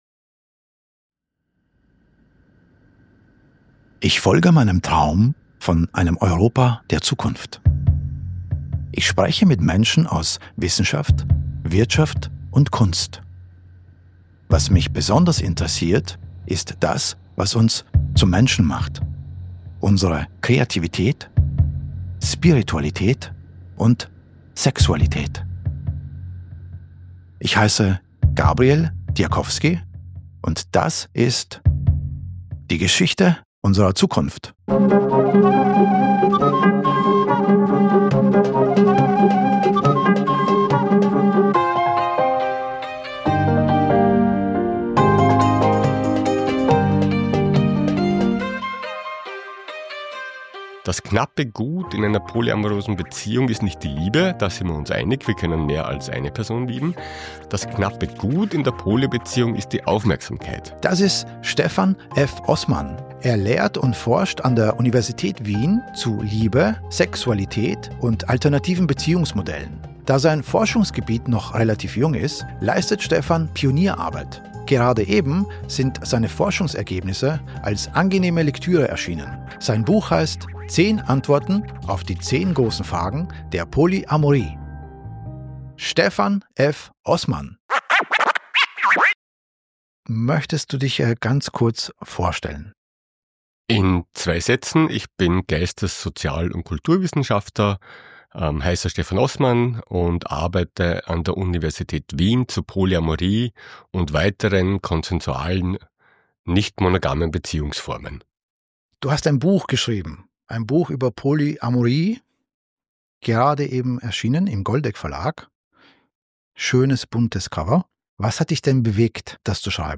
Kurz, bündig und mit voller Stimme.